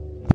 beeb kick 27 (slap)
Tags: 808 drum cat kick kicks hip-hop